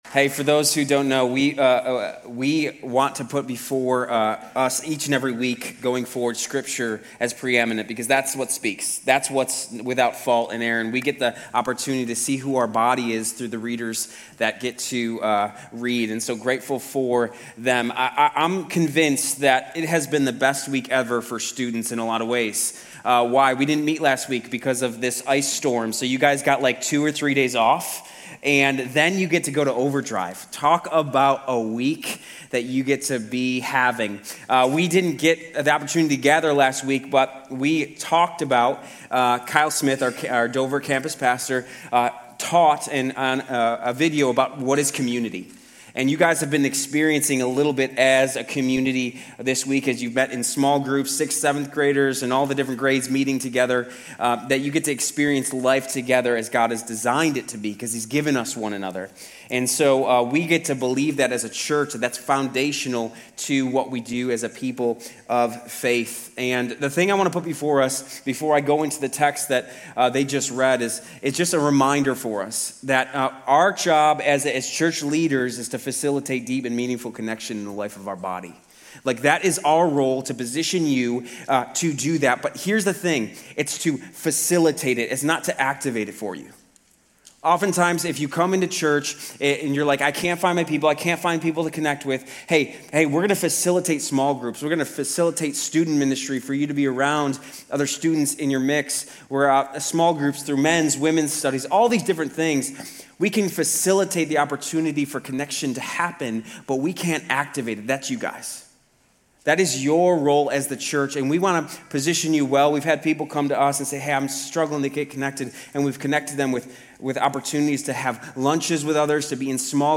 2_1 University Blvd Sermon